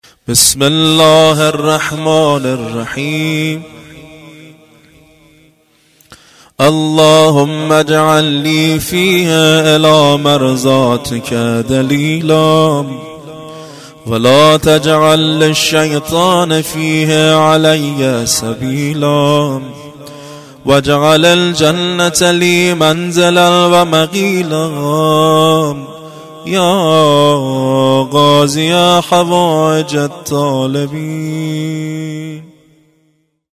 خیمه گاه - هیئت زواراباالمهدی(ع) بابلسر - دعای روزبیست ویکم ماه مبارک رمضان